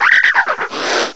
cry_not_yungoos.aif